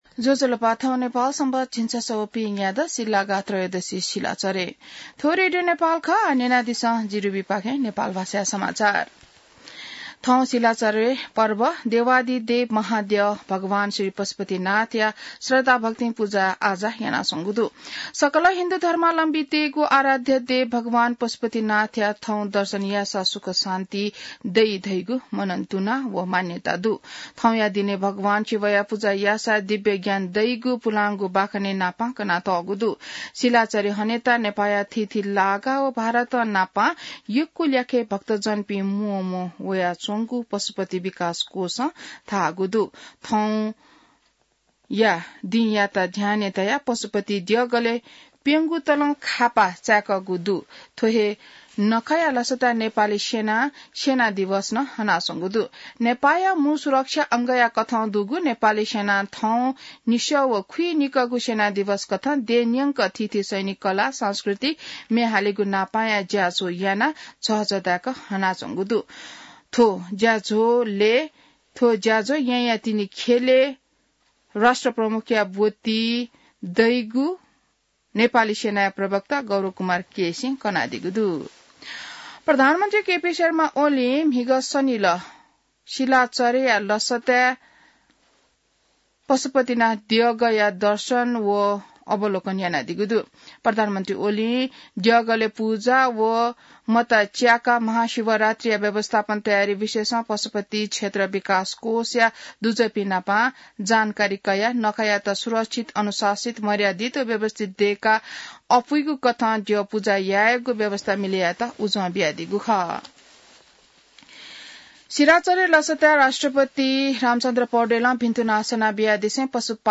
नेपाल भाषामा समाचार : १५ फागुन , २०८१